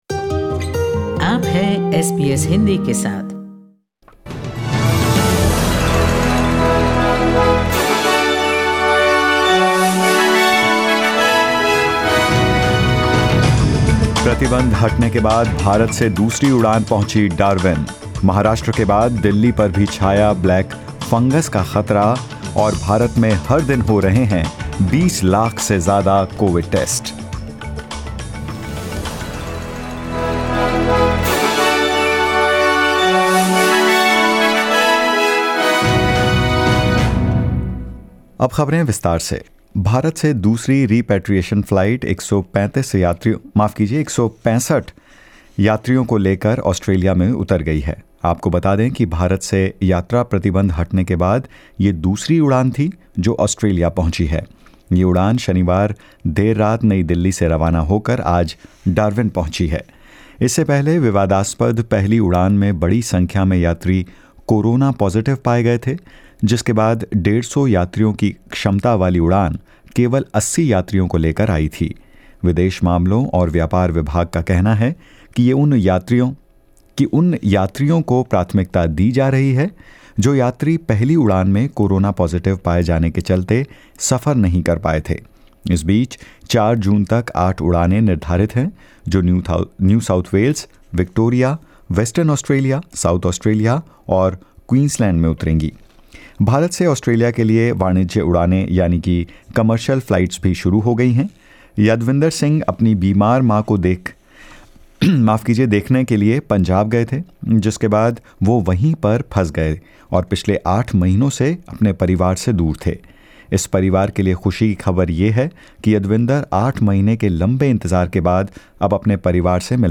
In this latest SBS Hindi News bulletin of India and Australia: With more than 4000 deaths, ‘Black fungus’ adds to India’s woes; India conducts more than 21 lakh COVID-19 tests in a single day and more.